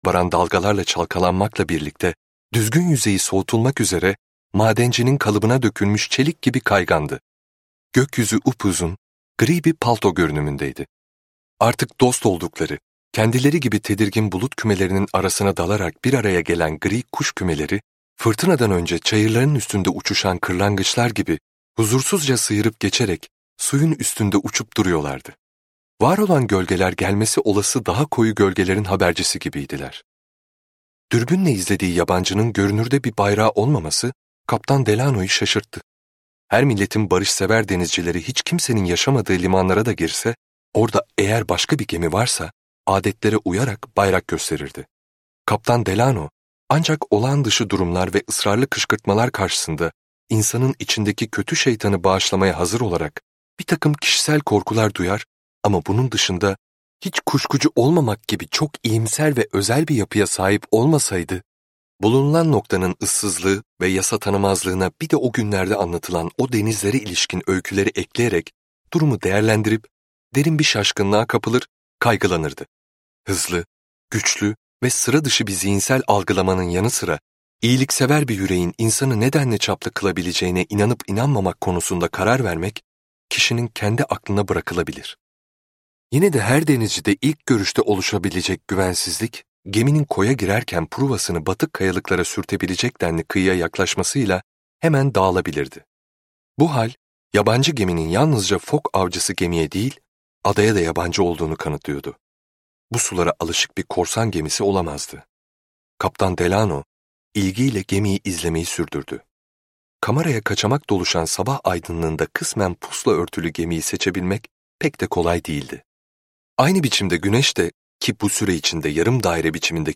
Benito Cereno - Seslenen Kitap
Seslendiren